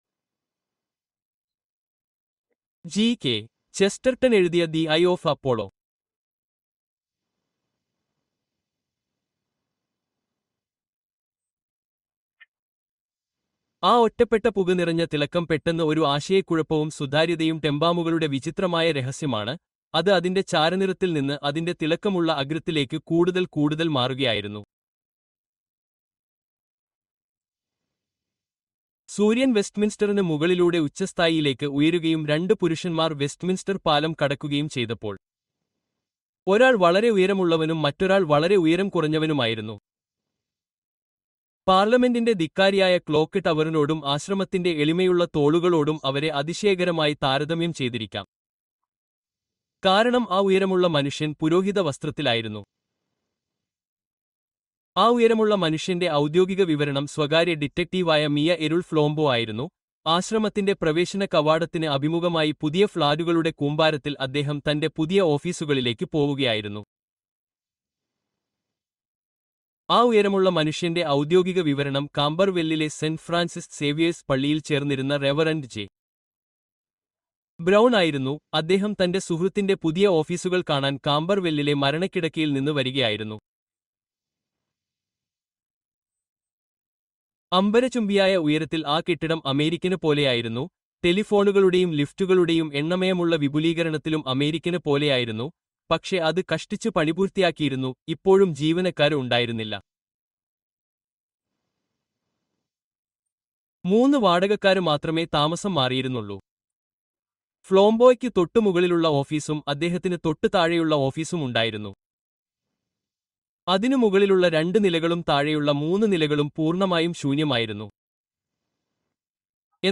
The Abbey Grange: Conan Doyle’s Masterful Mystery (Audiobook)